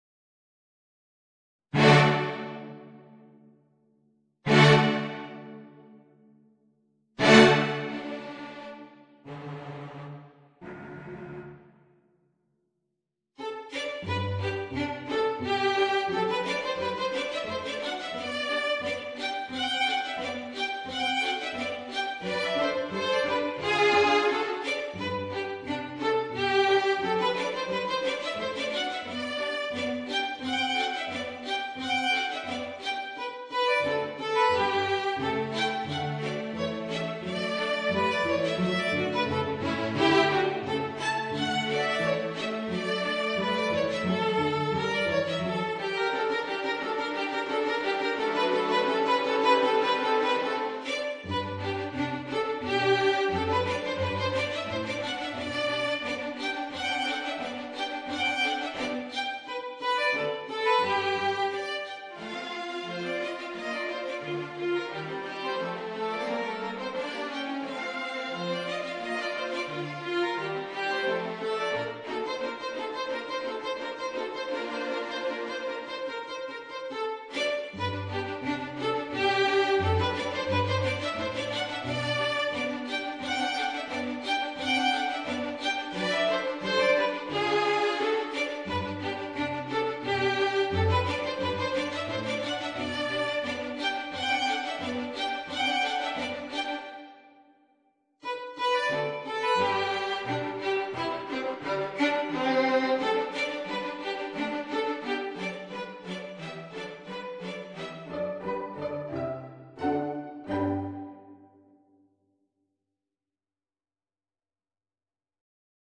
Voicing: Bassoon and String Quartet